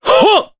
Gau-Vox_Attack1_jp.wav